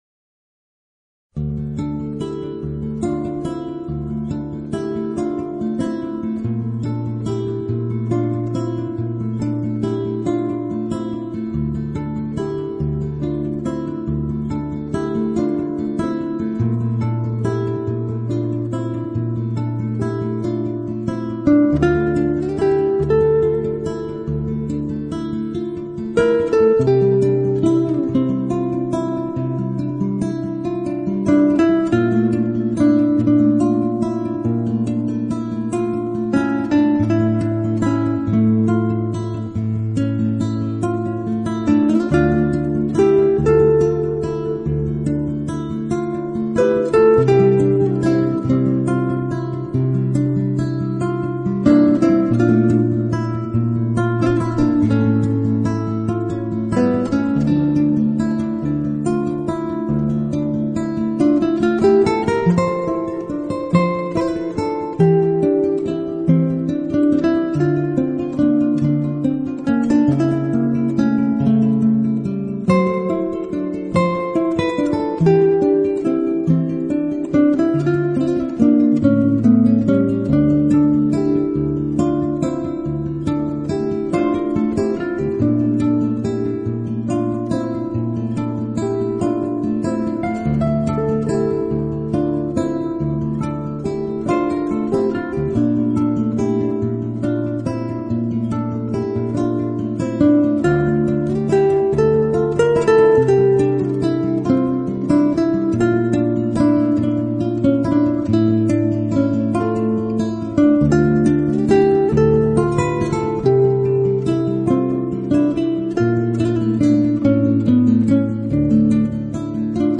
【纯音乐】
一首耳熟能详的英国传统歌谣，经由吉他声如珠玉般的演奏，让人仿佛能眼见到飞雪的飘零。